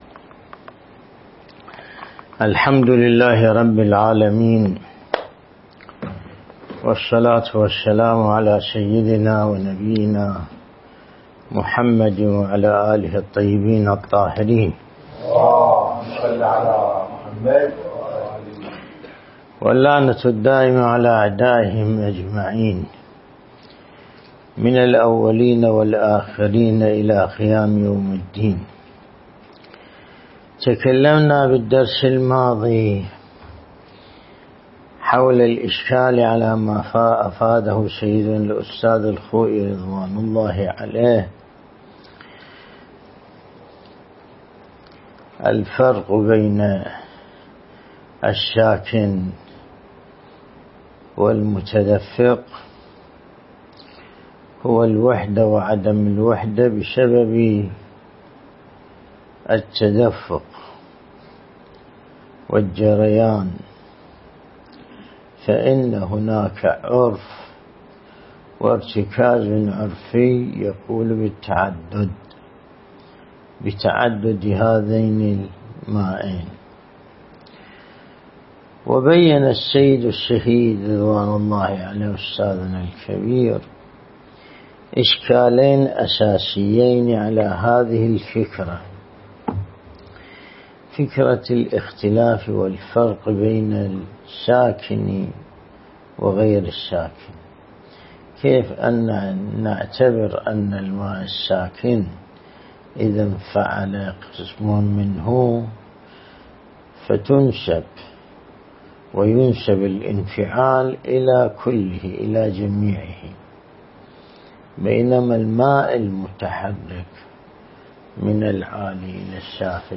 الدرس الاستدلالي